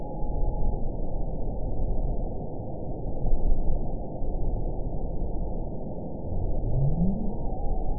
event 912118 date 03/18/22 time 19:45:07 GMT (3 years, 2 months ago) score 9.44 location TSS-AB04 detected by nrw target species NRW annotations +NRW Spectrogram: Frequency (kHz) vs. Time (s) audio not available .wav